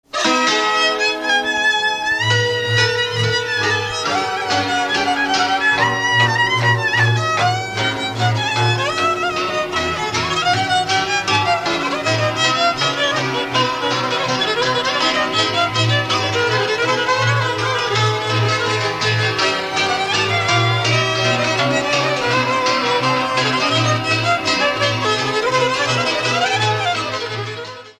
Dallampélda: Hangszeres felvétel
Erdély - Udvarhely vm. - Zetelaka
hegedű
cimbalom
bőgő
Műfaj: Lassú csárdás
Stílus: 1.1. Ereszkedő kvintváltó pentaton dallamok